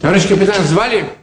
Worms speechbanks
Yessir.wav